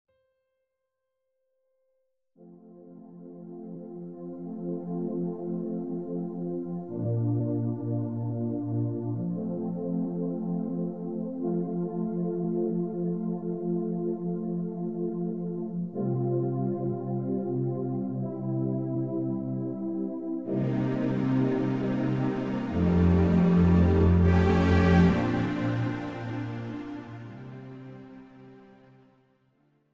This is an instrumental backing track cover.
• Key – C
• Without Backing Vocals
• No Fade
Backing Track without Backing Vocals.